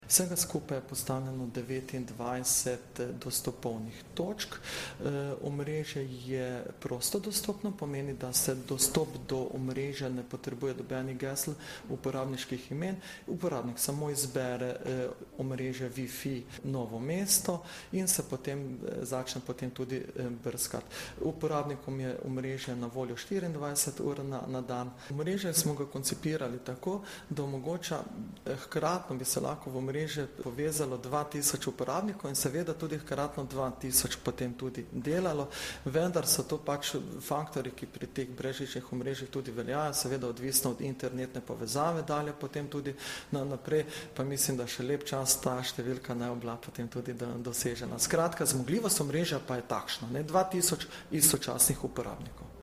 Novinarska konferenca o vzpostavitvi javnega brezplačnega brezžičnega omrežja WiFi Novo mesto